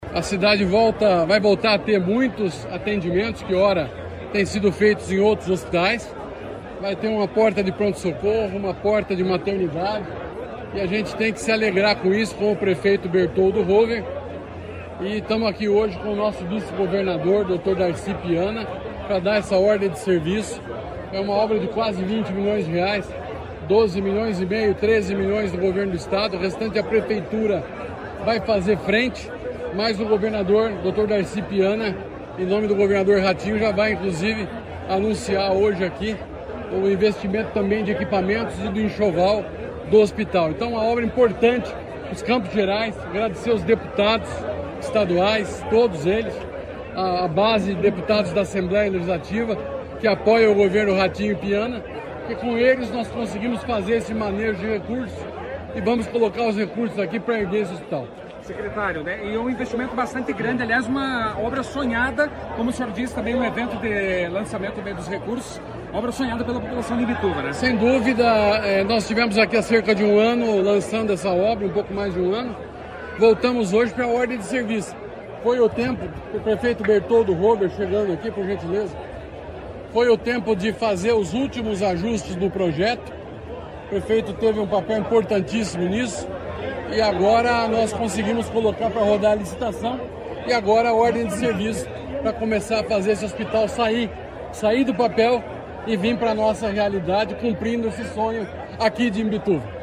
Sonora do secretário Estadual da Saúde, Beto Preto, sobre a autorização da obra do Hospítal Municipal de Imbituva